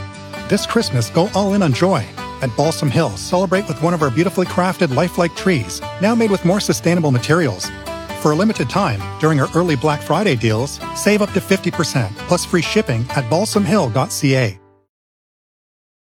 Adult (30-50)